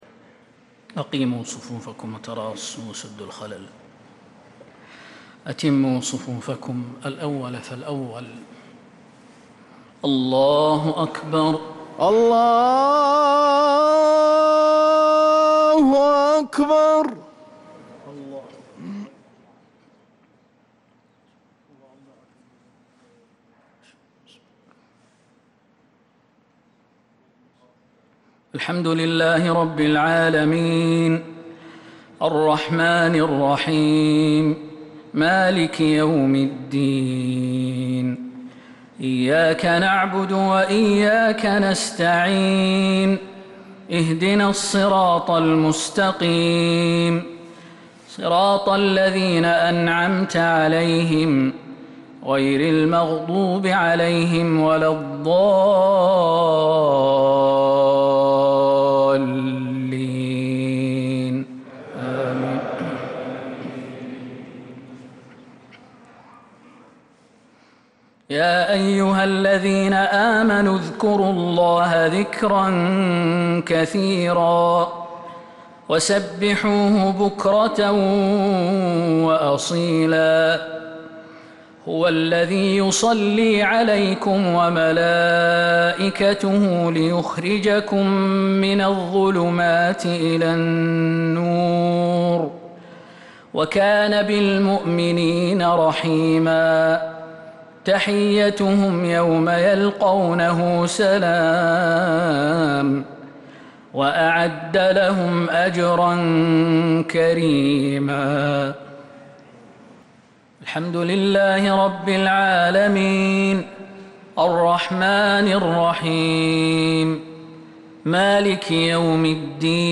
صلاة الجمعة 13 ربيع الأول 1447هـ | من سورة الأحزاب 41-48 | Jumu’ah prayer from Surah Al-ahzab 5-9-2025 > 1447 🕌 > الفروض - تلاوات الحرمين